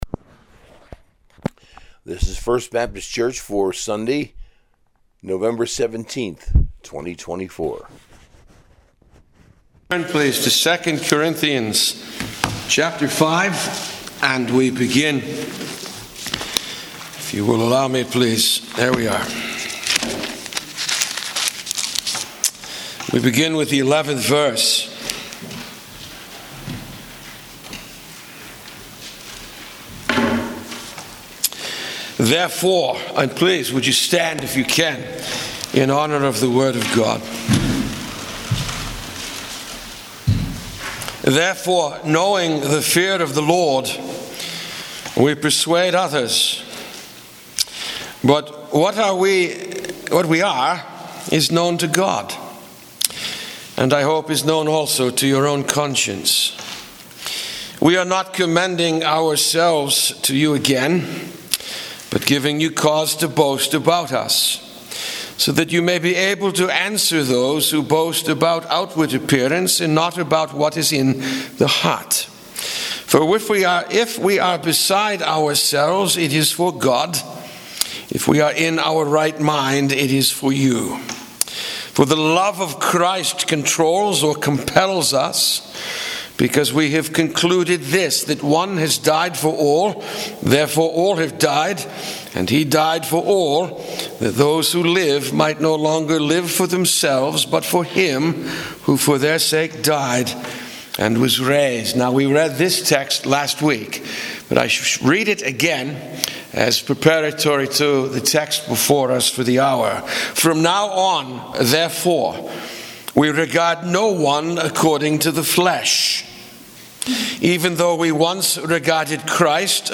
Sunday Sermon, taken from II Corinthians 5:11-End, Part II